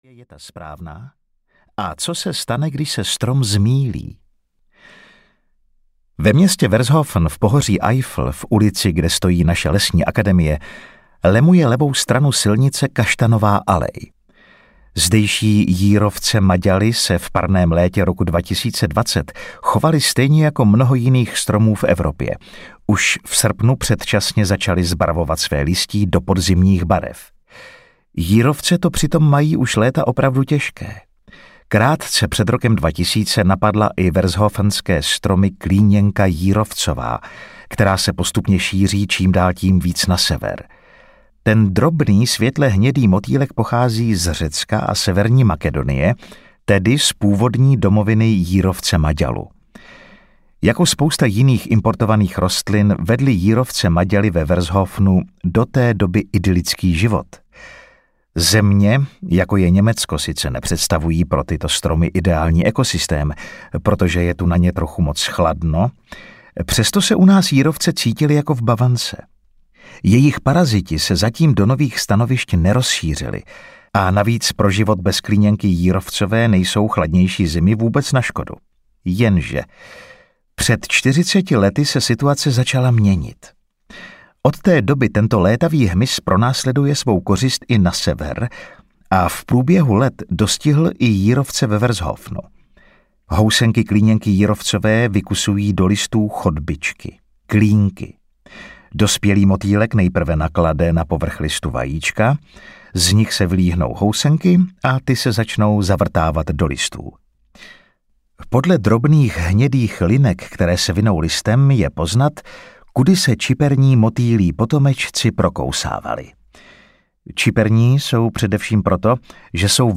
Než stromům dojde dech audiokniha
Ukázka z knihy